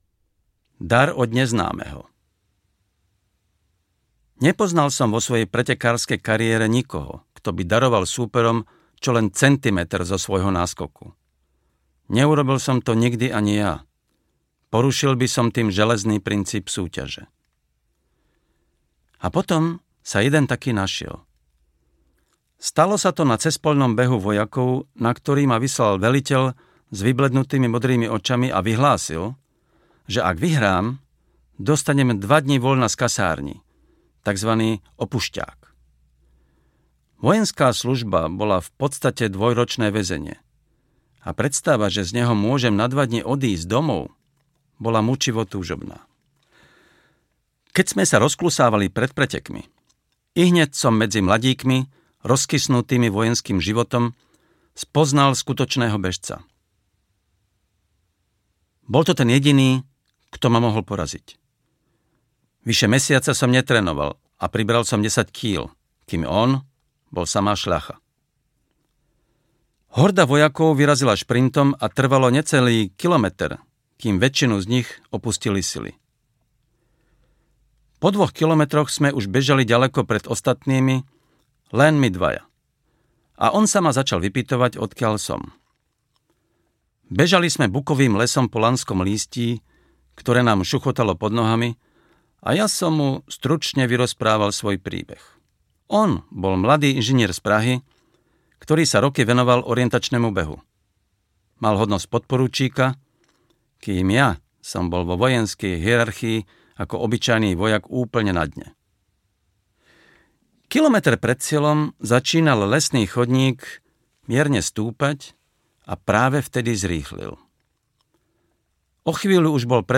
Telesná výchova audiokniha
Ukázka z knihy
• InterpretMartin M. Šimečka